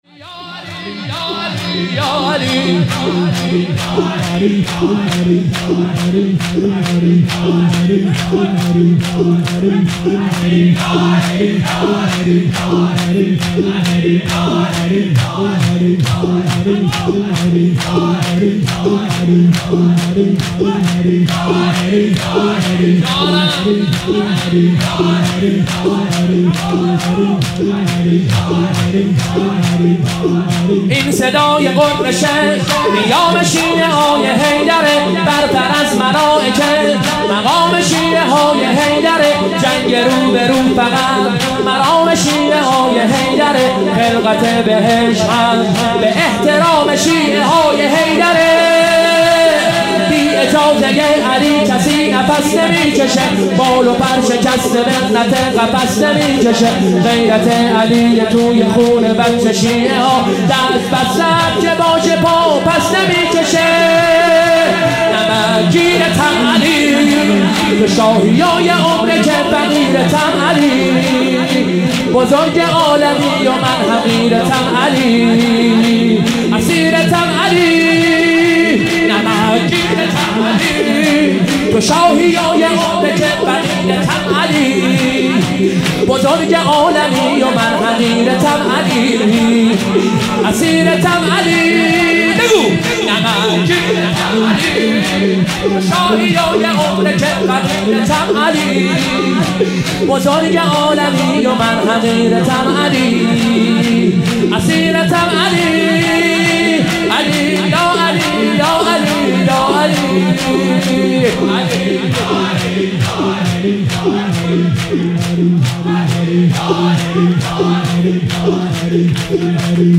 شور، زمزمه